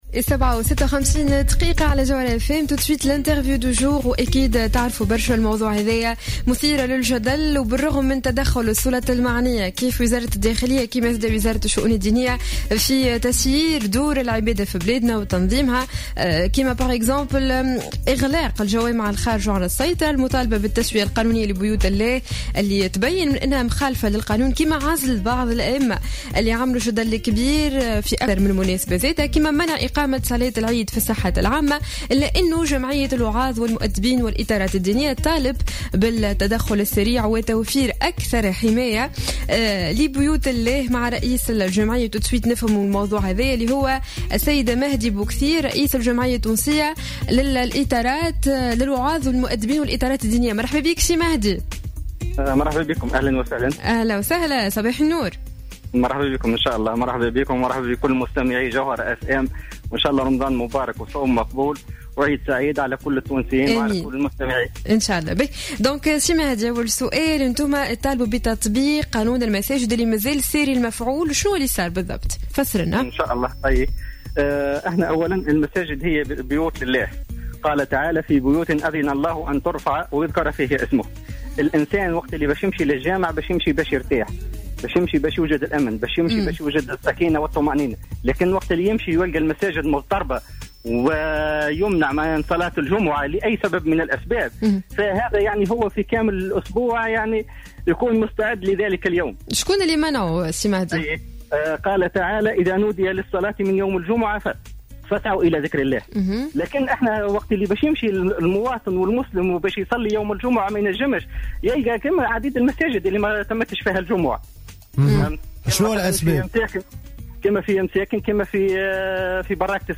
في تصريح لجوهرة أف أم في برنامج صباح الورد